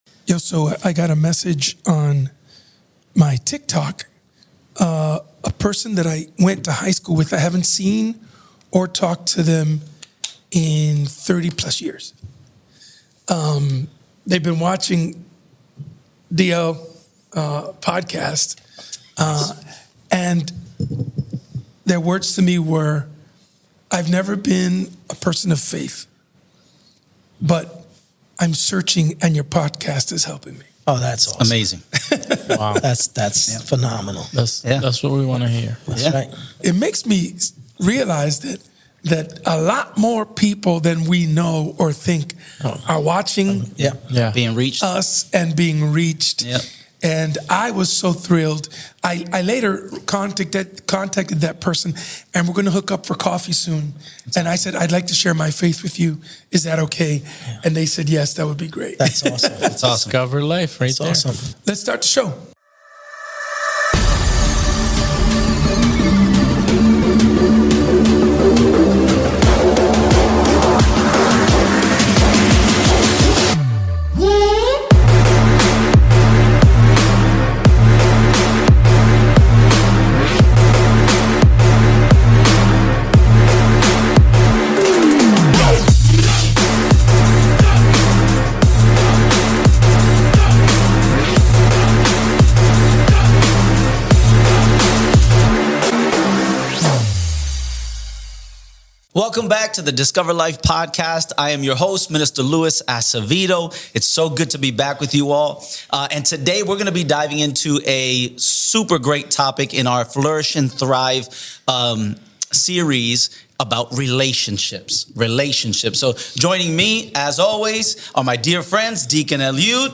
Join us for a heartfelt discussion that aims to inspire and equip you to flourish in your relationships, both with loved ones and within your community.